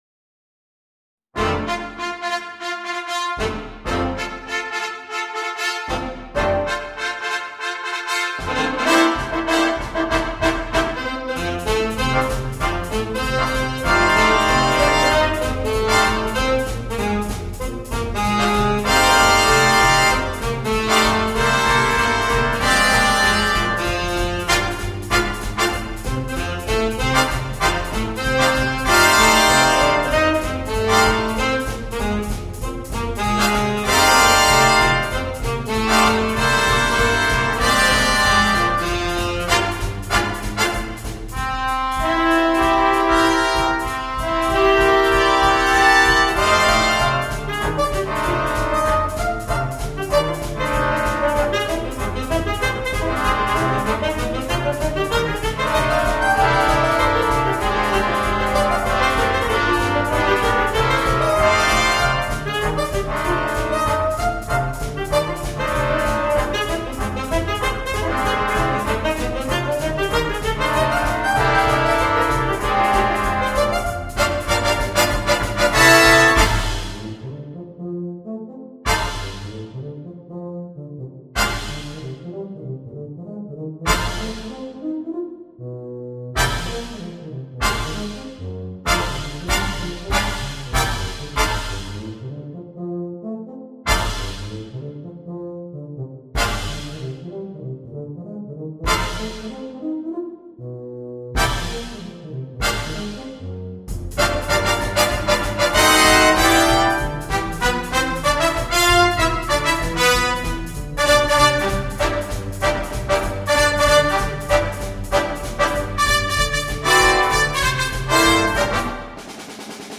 для джаз-бэнда.